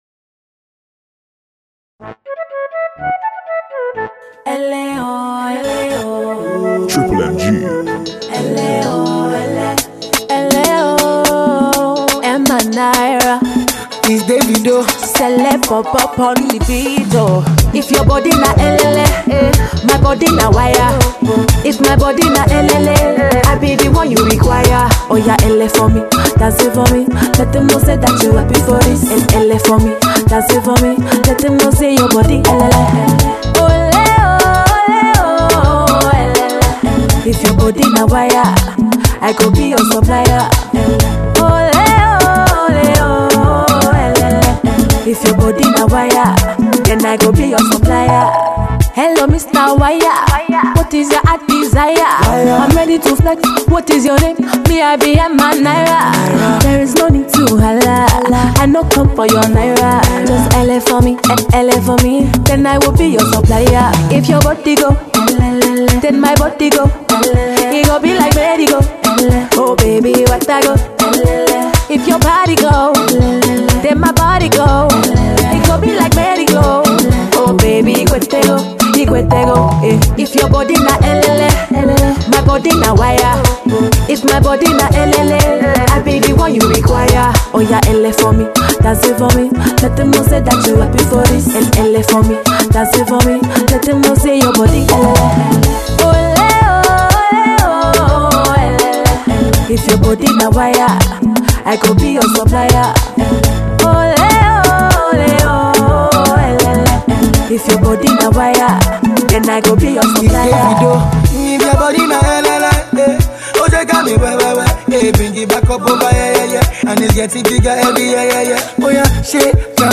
up tempo track